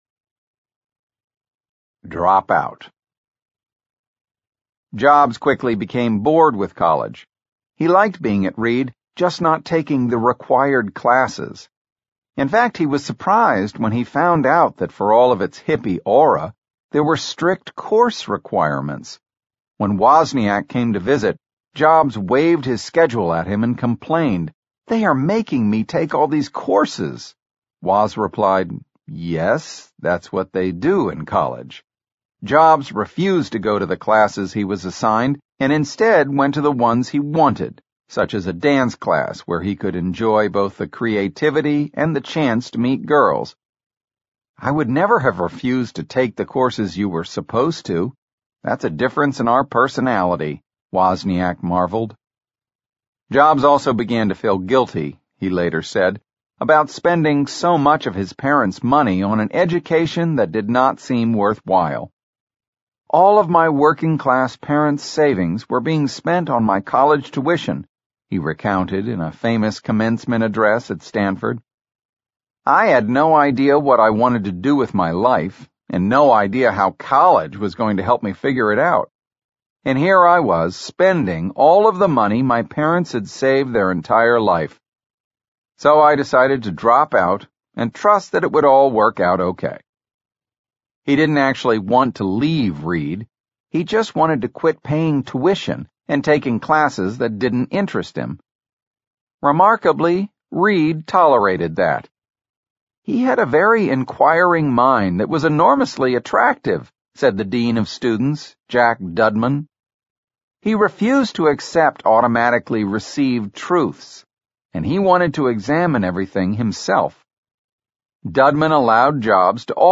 在线英语听力室乔布斯传 第37期:退学不离校(1)的听力文件下载,《乔布斯传》双语有声读物栏目，通过英语音频MP3和中英双语字幕，来帮助英语学习者提高英语听说能力。
本栏目纯正的英语发音，以及完整的传记内容，详细描述了乔布斯的一生，是学习英语的必备材料。